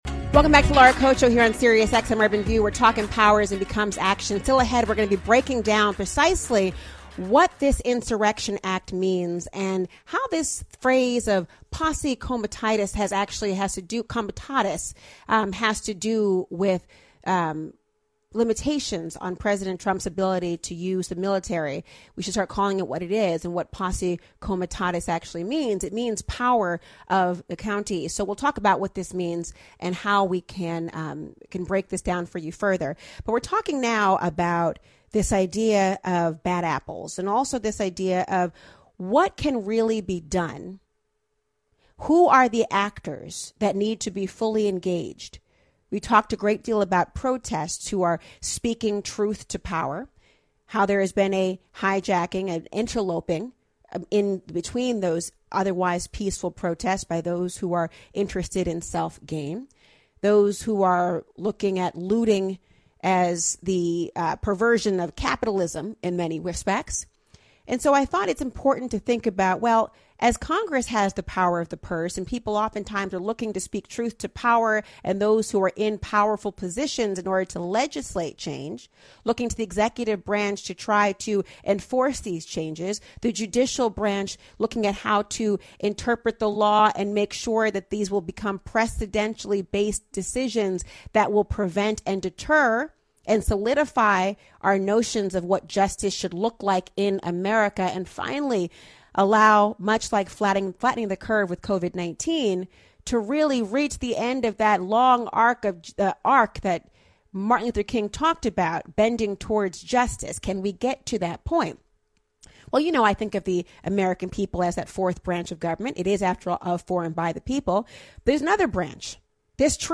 SiriusXM Interview